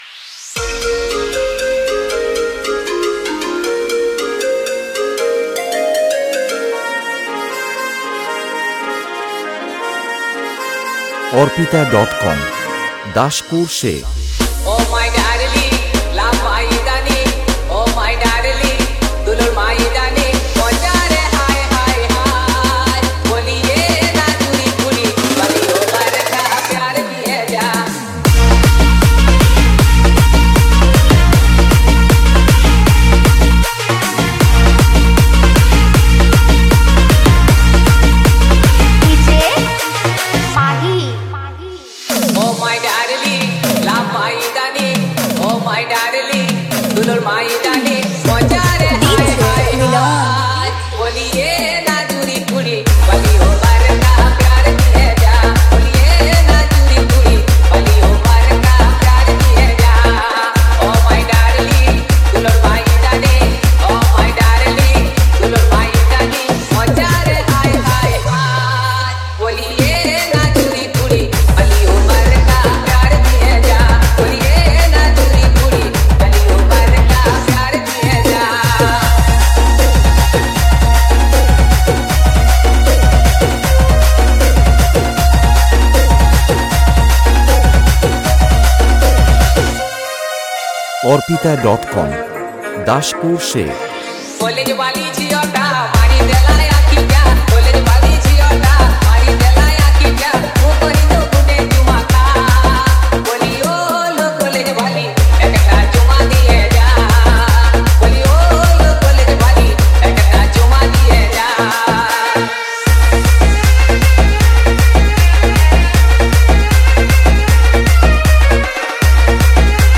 Purulia Bhojpuri EDM dancing song 2025